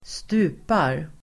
Uttal: [²st'u:par]